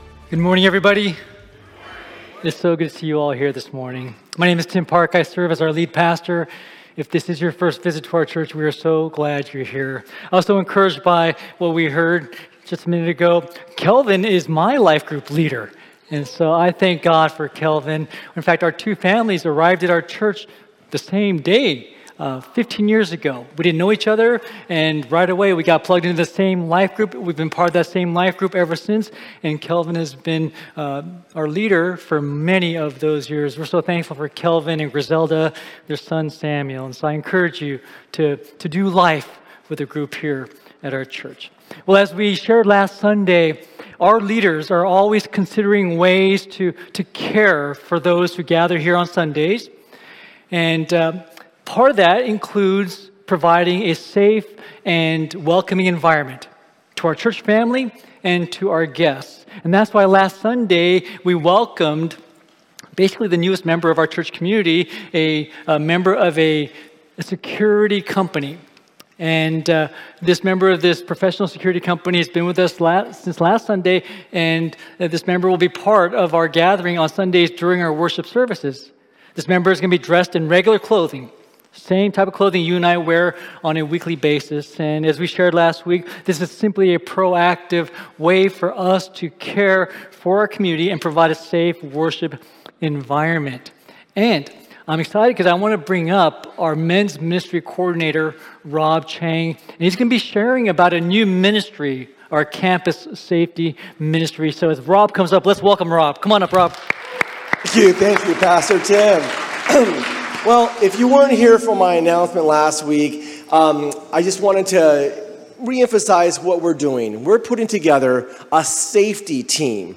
Sunday Sermons
Messages from Evangelical Free Church: Diamond Bar, CA